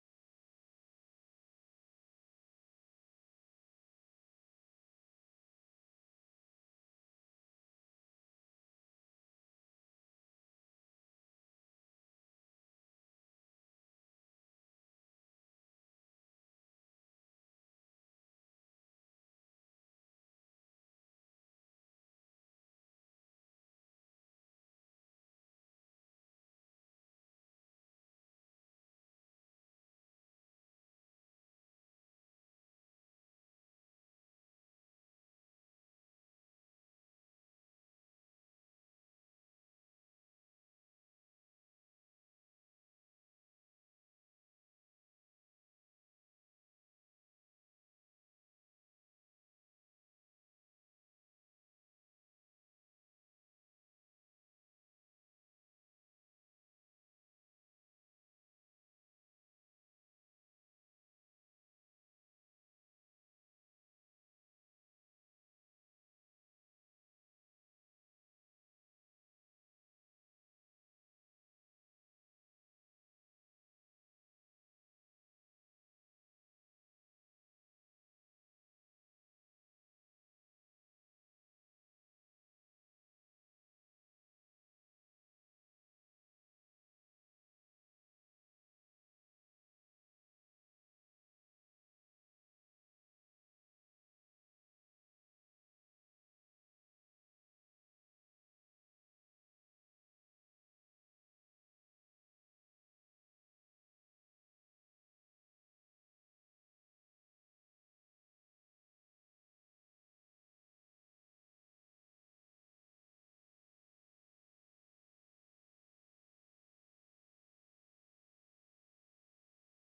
Praise and Worship on October 22 2023
lead us in worship to the Lord.